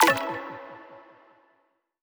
button-play-select.wav